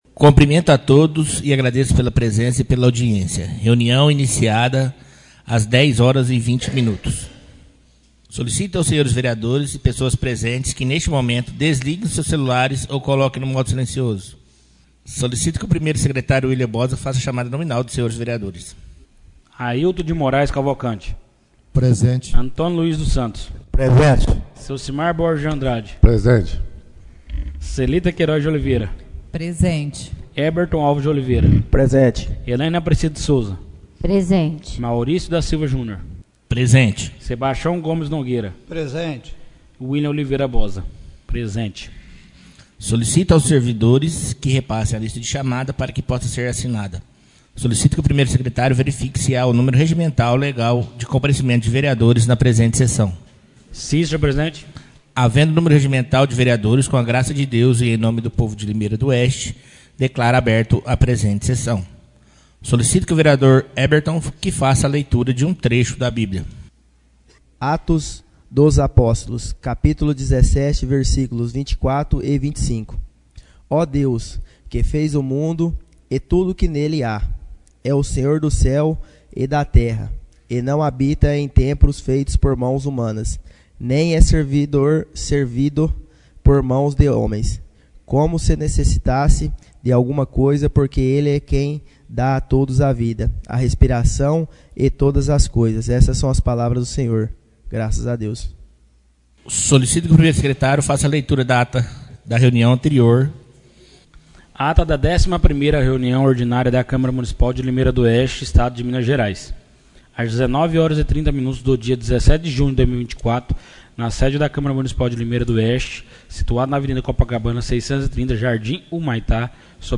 / Reuniões Ordinárias.